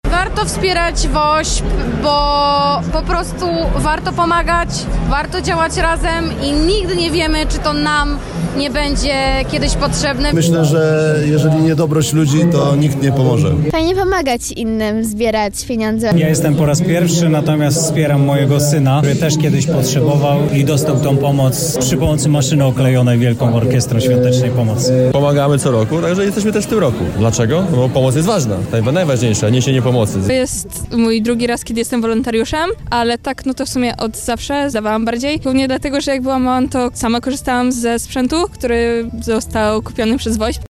Co sprawia, że co roku tysiące osób zakładają identyfikatory, a kolejne tysiące z uśmiechem wrzucają pieniądze do puszek? O motywacje, emocje i znaczenie tej akcji zapytaliśmy wolontariuszy z Lublina oraz osoby wspierające WOŚP:
SONDA